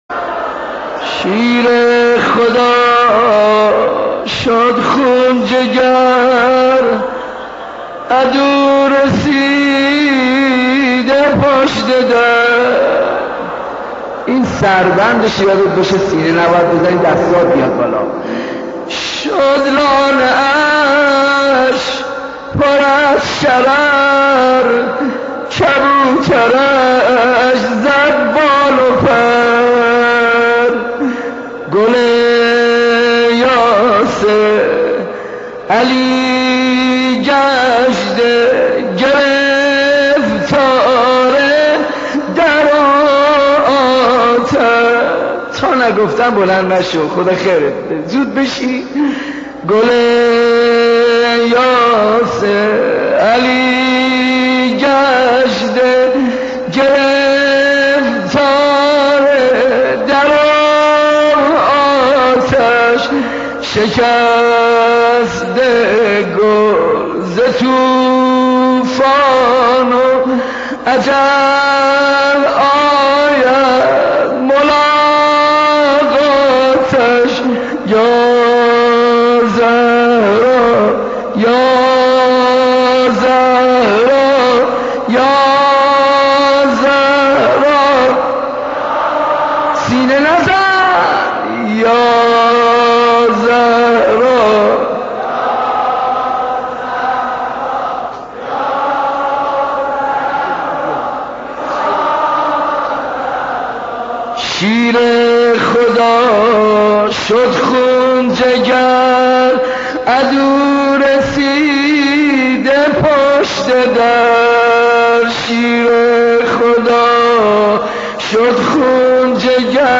مداحی
روضه حضرت زهرا